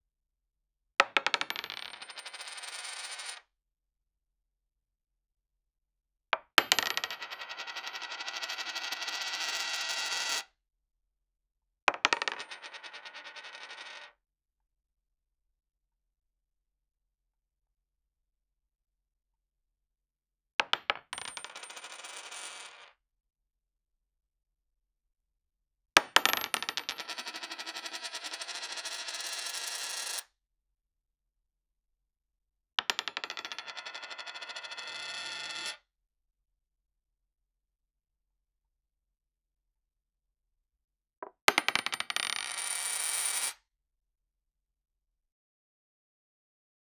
several many coins falling in a table at the same time
several-many-coins-fallin-opnpmdos.wav